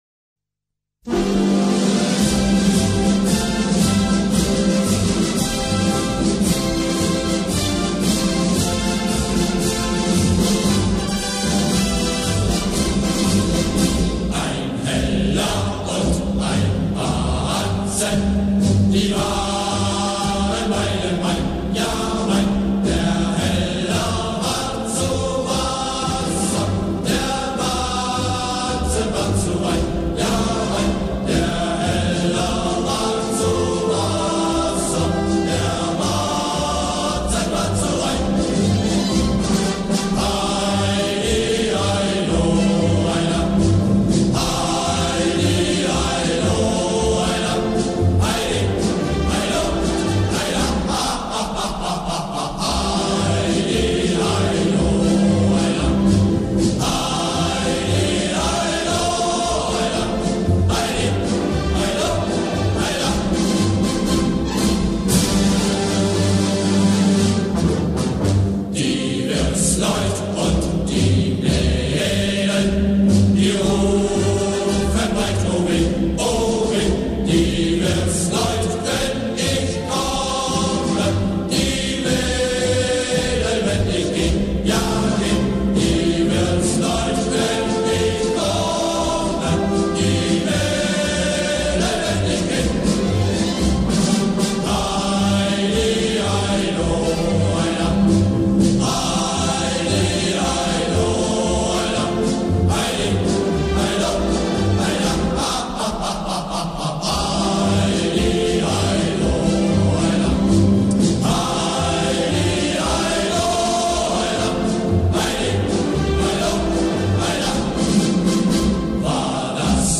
10 German Marching Songs